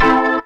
B3 AMIN 1.wav